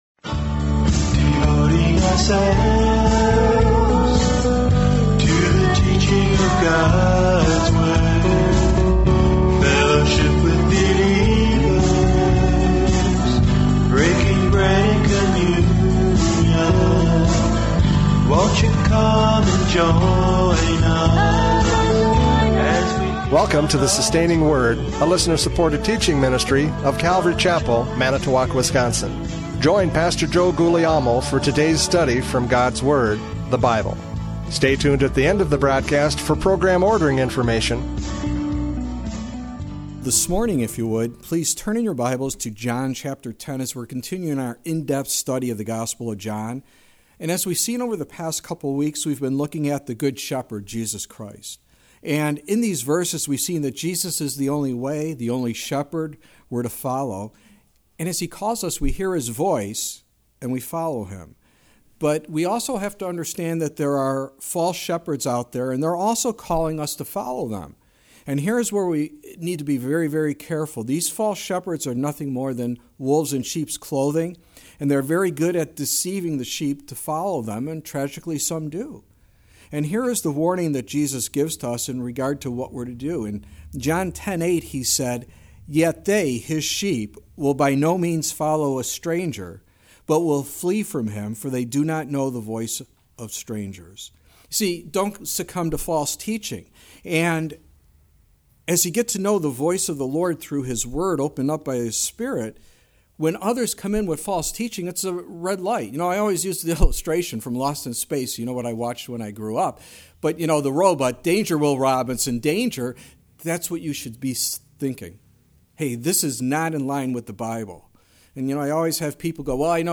John 10:22-42 Service Type: Radio Programs « John 10:11-21 The Good Shepherd!